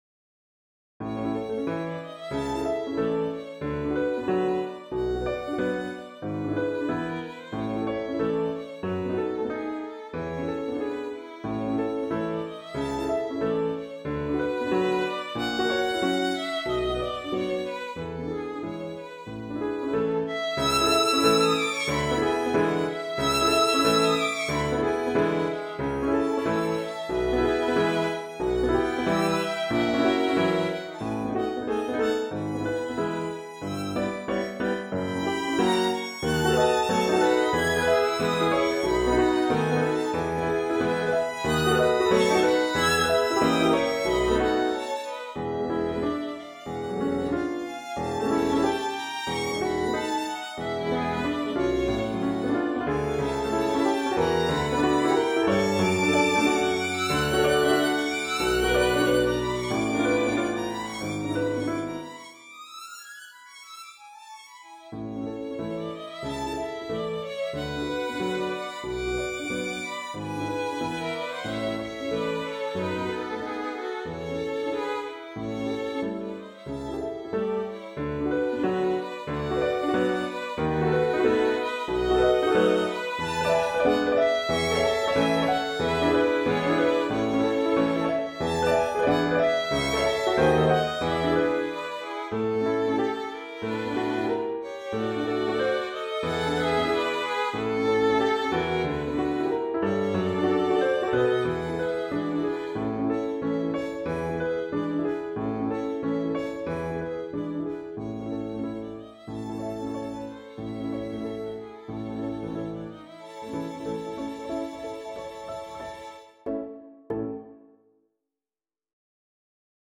【編成】ヴァイオリン二重奏とピアノ（2 Violins and Piano）